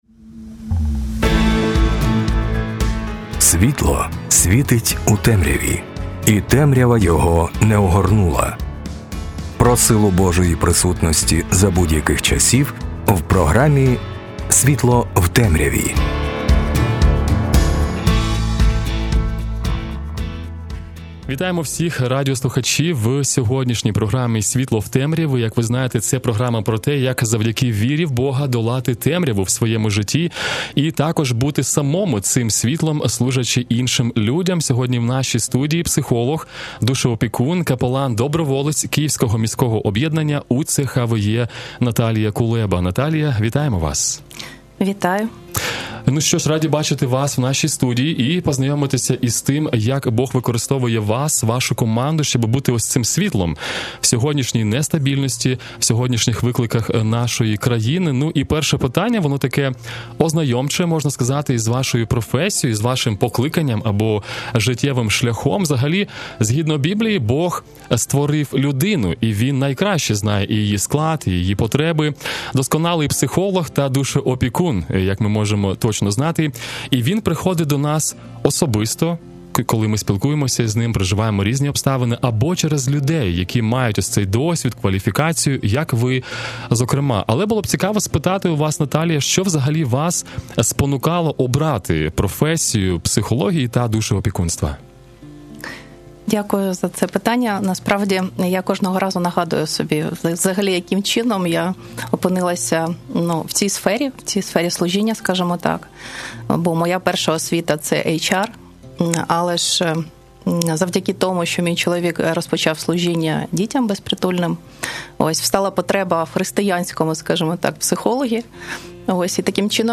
Також ми дізнались більше про напрямки капеланства, його суть та процес входження в цю потрібну та важливу місію. Інтерв'ю також...